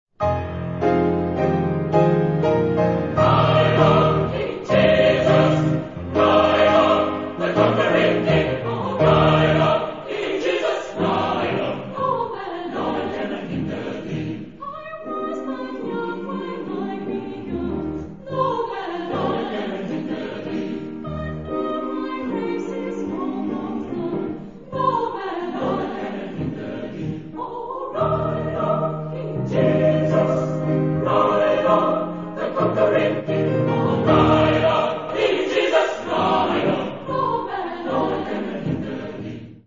Genre-Style-Form: Spiritual ; Sacred ; Gospel
Mood of the piece: joyous ; rhythmic
Type of Choir: SATB (div)  (4 mixed voices )
Soloist(s): Soprano (1)  (1 soloist(s))
Instrumentation: Piano  (1 instrumental part(s))
Tonality: major